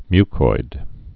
(mykoid)